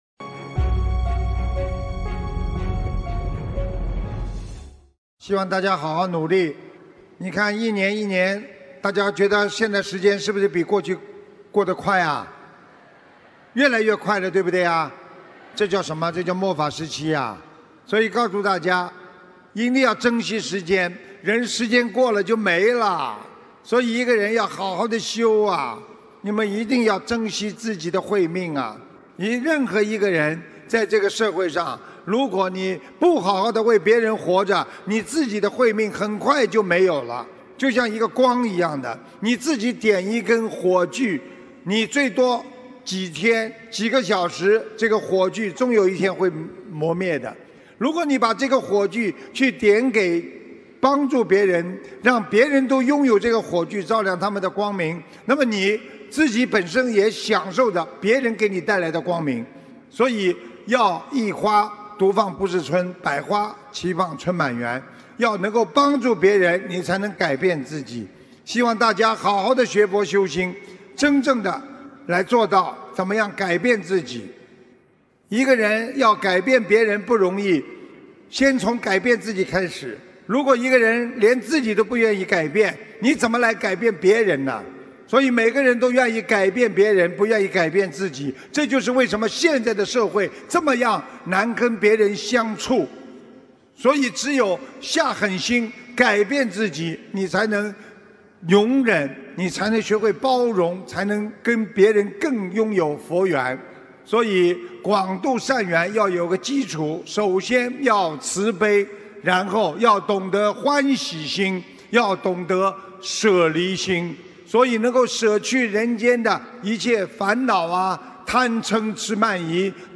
2019年10月19日马来西亚吉隆坡世界佛友见面会结束语-经典感人开示节选